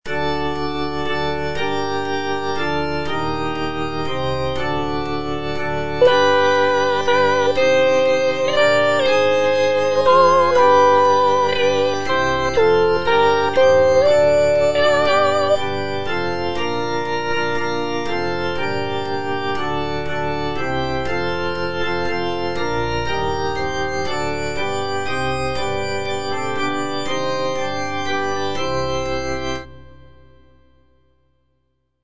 G.P. DA PALESTRINA - STABAT MATER Eja Mater, fons amoris (soprano I) (Voice with metronome) Ads stop: auto-stop
sacred choral work